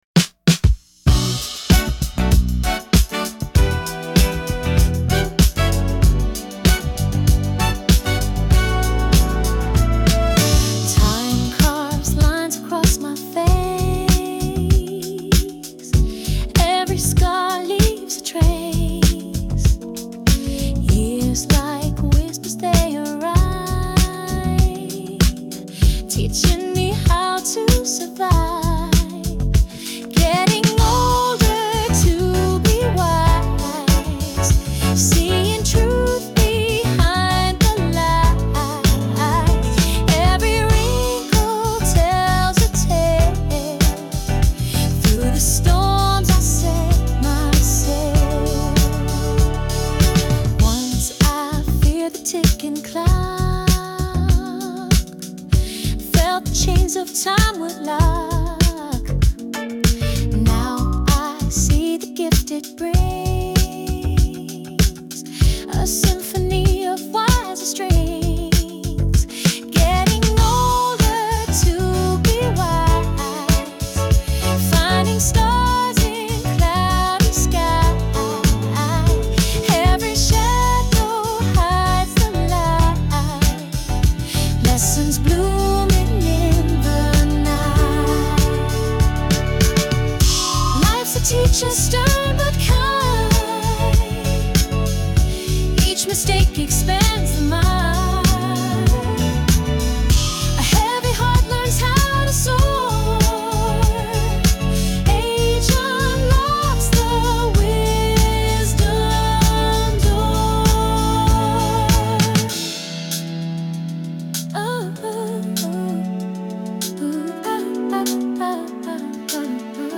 Genre:                   R&B/Soul (Dance)